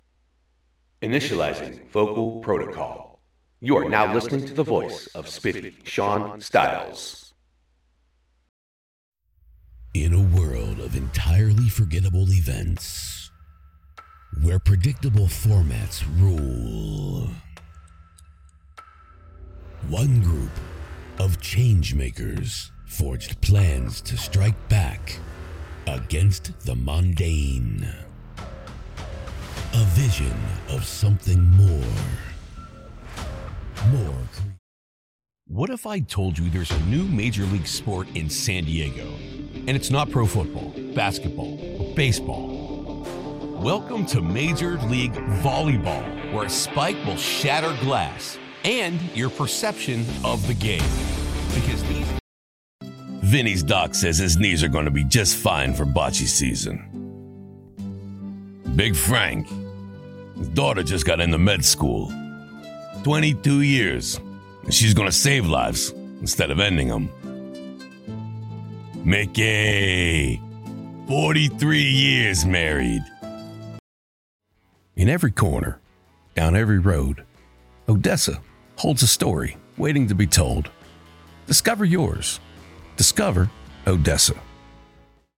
English - USA and Canada